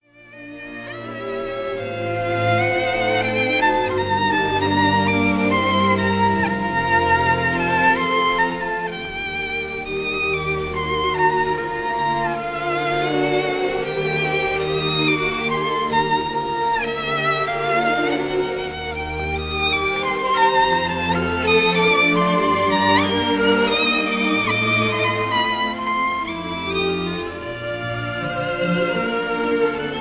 This is a 1930 recording
violin